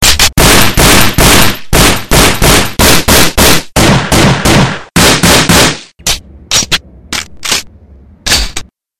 fire-war_24755.mp3